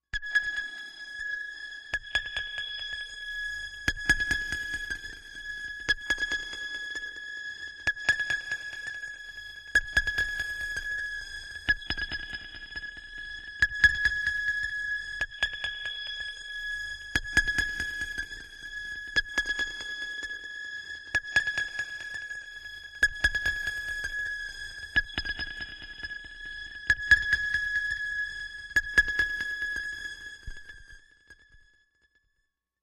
Satellite Song, Machine, Space Satellite, Sonar, Alien, Lost